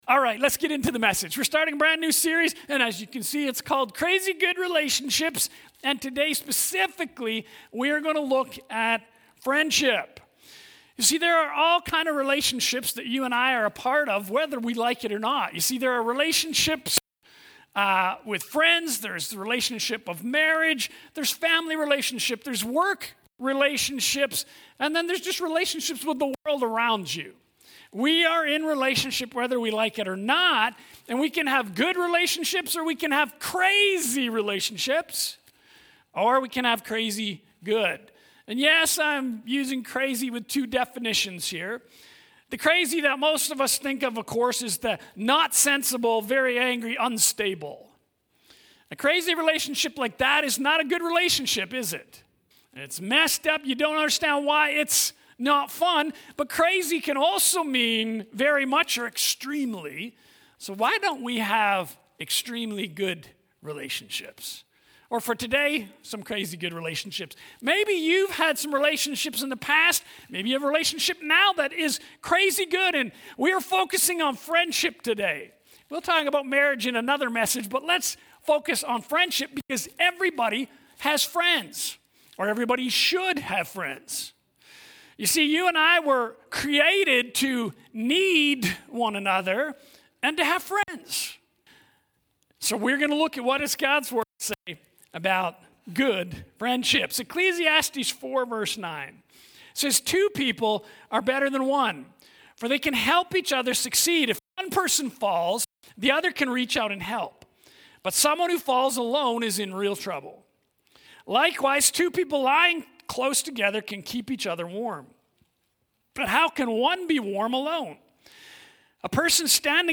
Sermons | Abundant Life Worship Centre